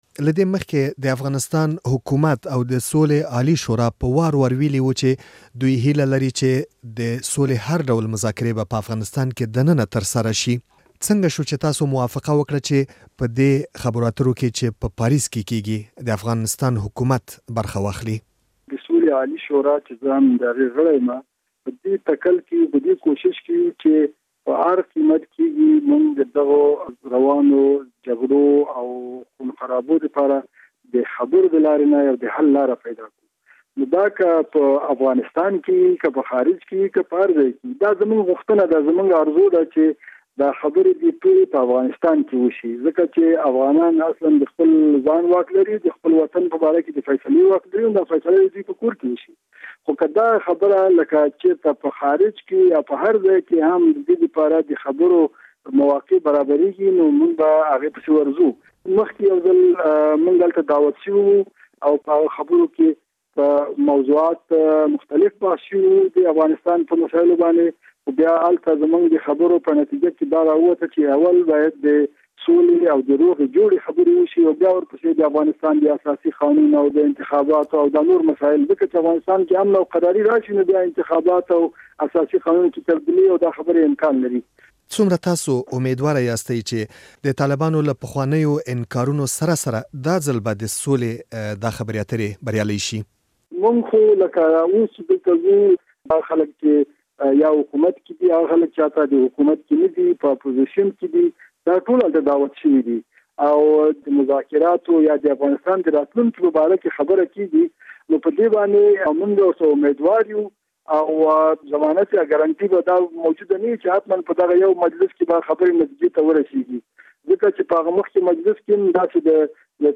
له حاجي دين محمد سره مرکه